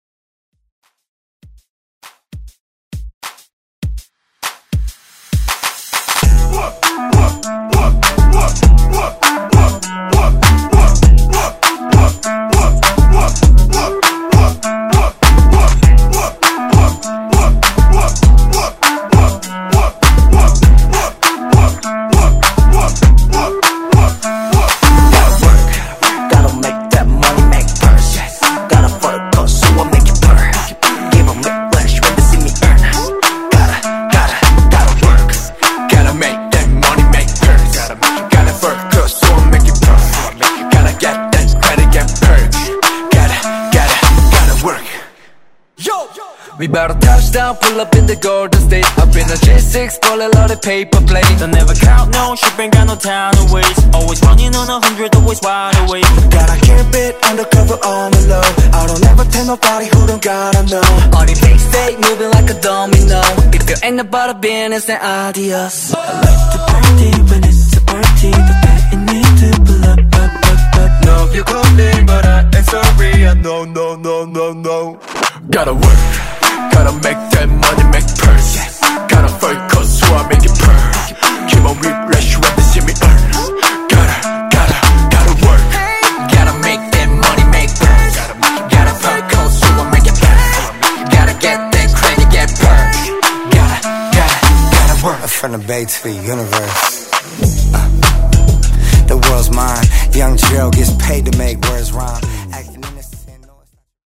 Genres: FUTURE HOUSE , TOP40
Clean BPM: 126 Time